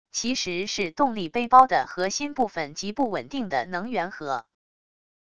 其实是动力背包的核心部分－－极不稳定的能源核wav音频